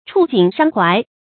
觸景傷懷 注音： ㄔㄨˋ ㄐㄧㄥˇ ㄕㄤ ㄏㄨㄞˊ 讀音讀法： 意思解釋： 見「觸景傷情」。